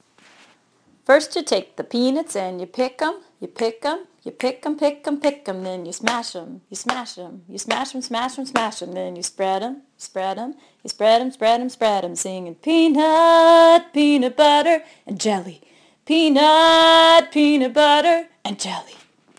A song I learned at Girl Scout camp a thousand years ago.  The version I sing goes like this (click on the triangle to hear the first verse):